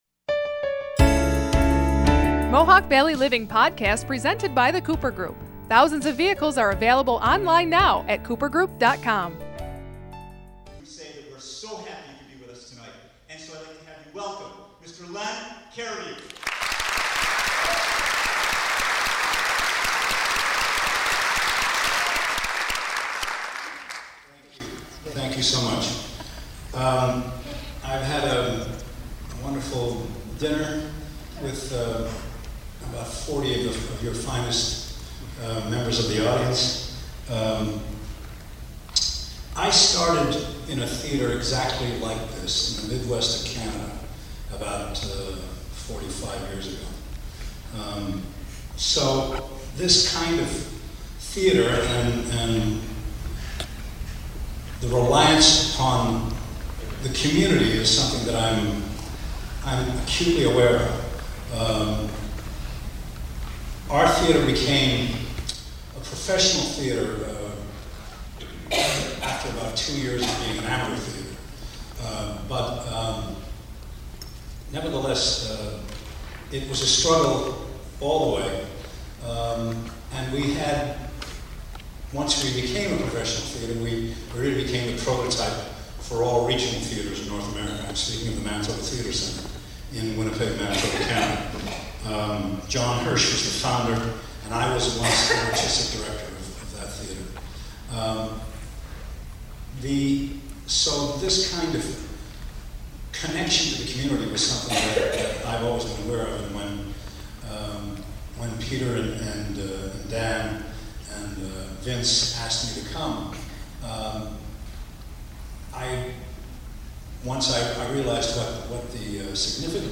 4. Len Cariou speech given at Sweeney Todd performance (audio not as clear)